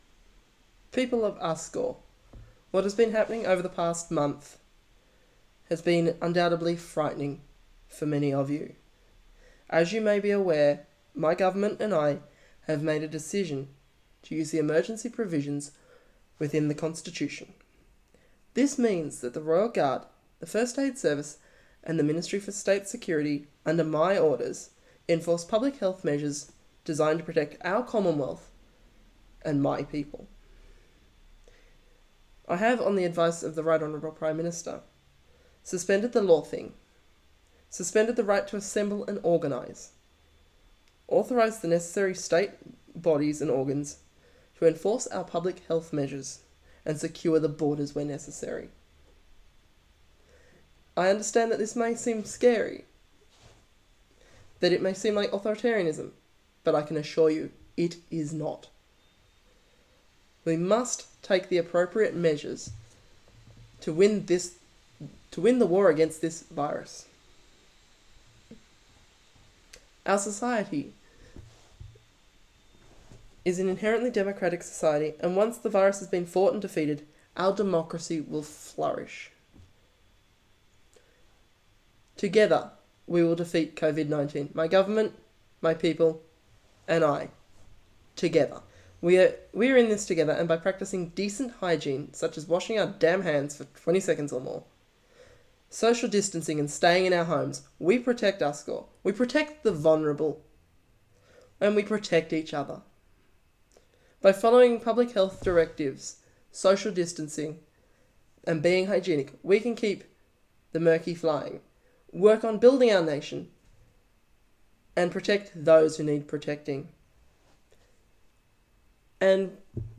Her Majesty's Speech can also be listened to here
Speaking from the locked down city of Freyavik, and flanked by huge Uskorian Merkis (flags) and an Aboriginal flag and Royal Standard, the High Queen spoke about what the Uskorian Government is doing to protect the people of Uskor.